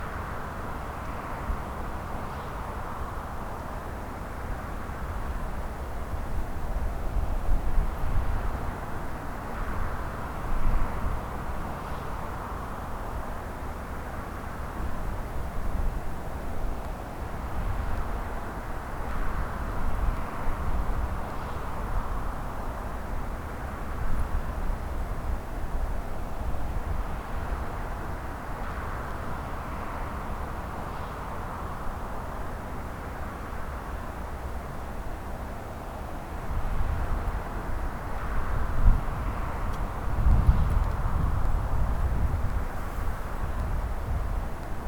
desert-highway-front.mp3